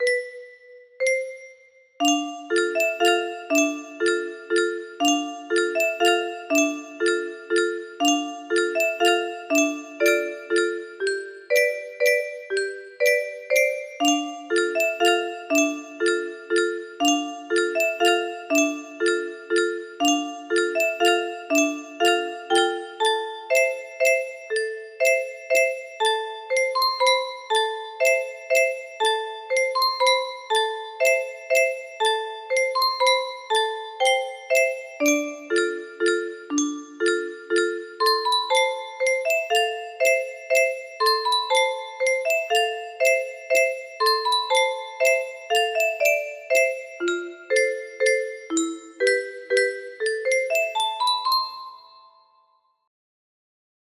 1st jingle music box melody